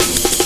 amen chopsnare2.wav